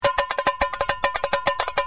バテリア＆サンバ楽器
tambo.mp3